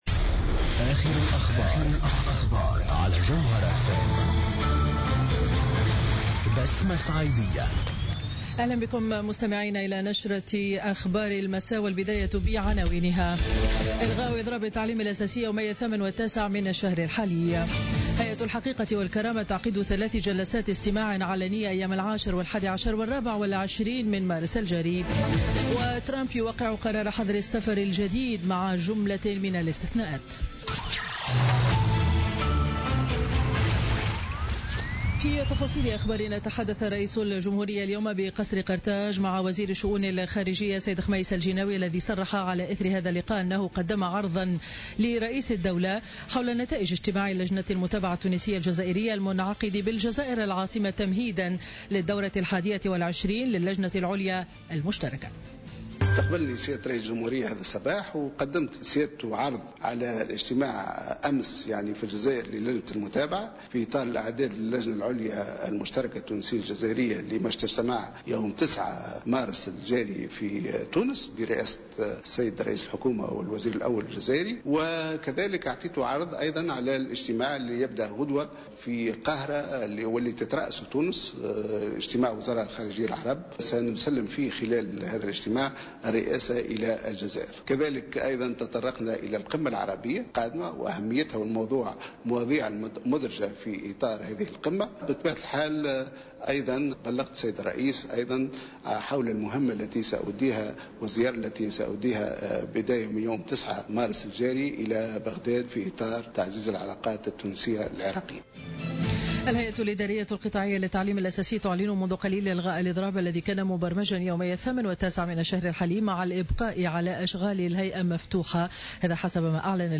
نشرة أخبار السابعة مساء ليوم الاثنين 6 مارس 2017